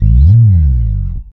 -MM SLIDE2.wav